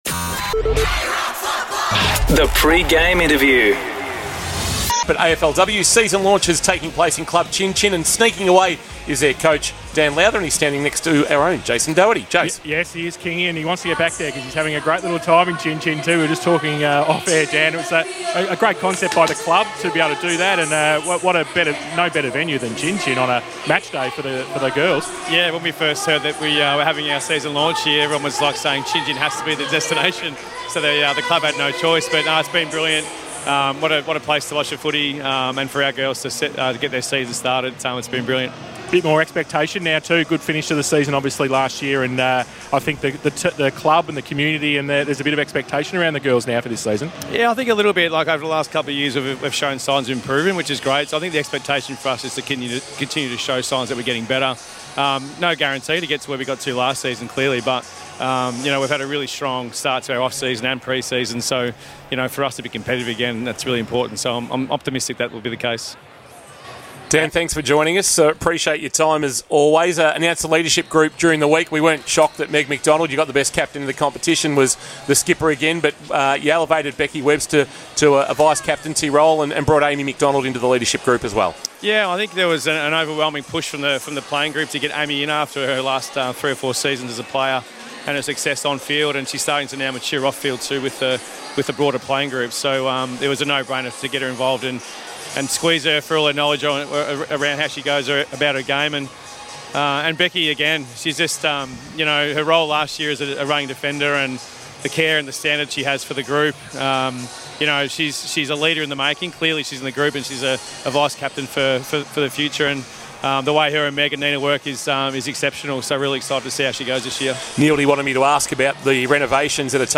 2024 - AFL - Round 21 - Geelong vs. Adelaide - Pre-match interview